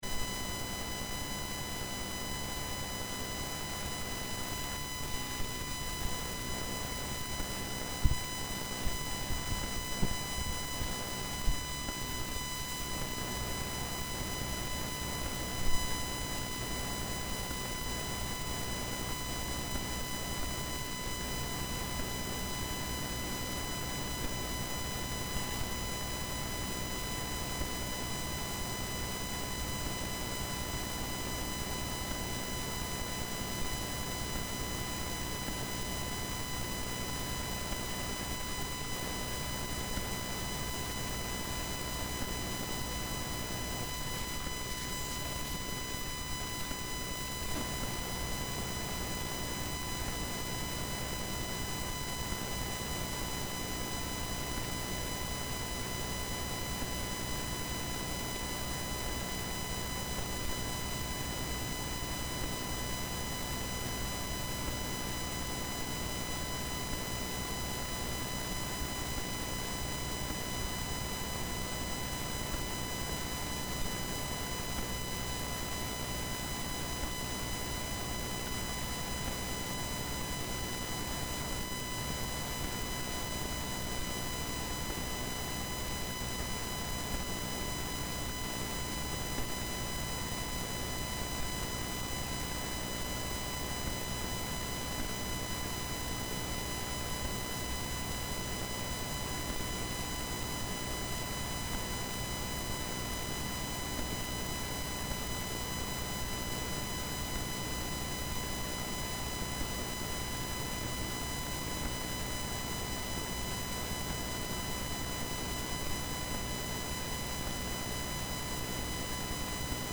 Luogo: Sala Consiglio - piazza Manzoni 1 - primo piano